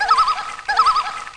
Amiga 8-bit Sampled Voice
bird.mp3